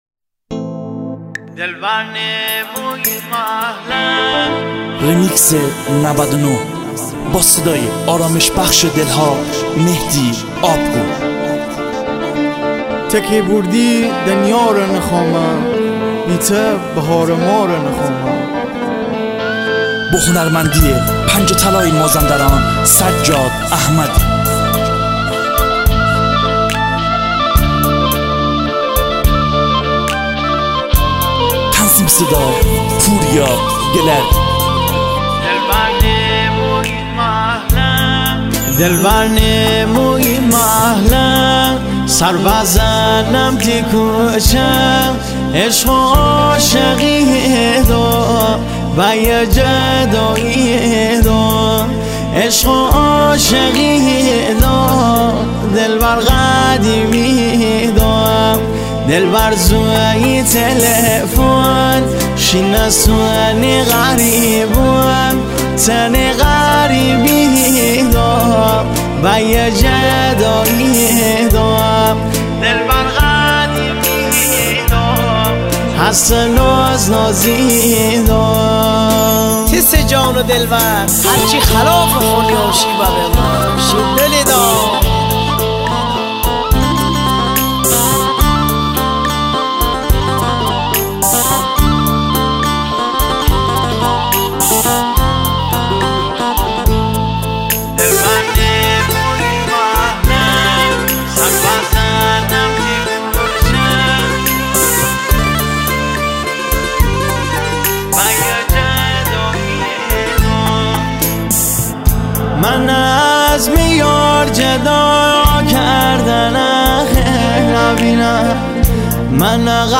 میکس آهنگ های ۹۹ مازندرانی